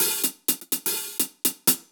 Index of /musicradar/ultimate-hihat-samples/125bpm
UHH_AcoustiHatA_125-05.wav